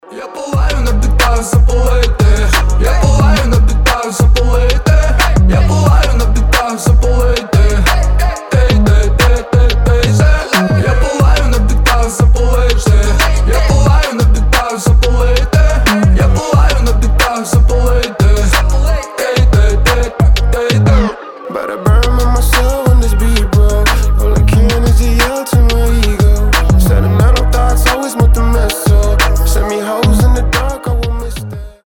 • Качество: 320, Stereo
дуэт
Чёткий, свежий украинский рэпчик